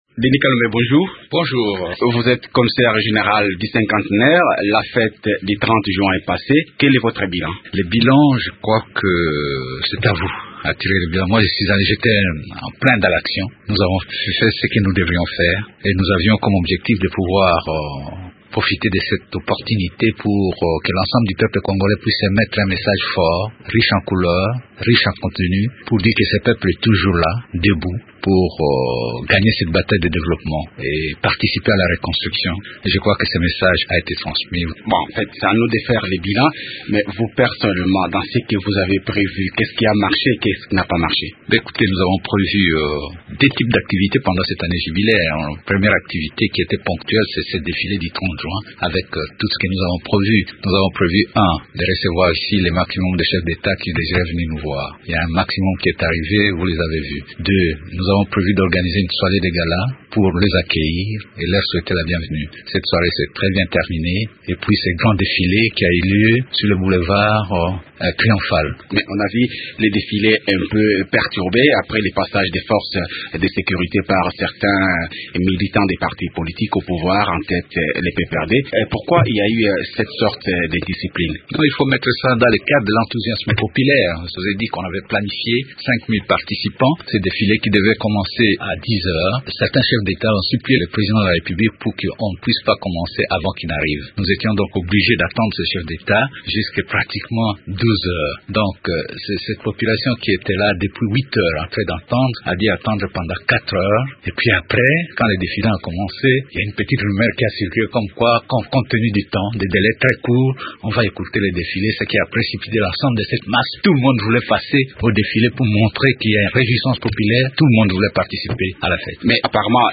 Le commissaire général du cinquantenaire s’est livré aux questions de Radio Okapi.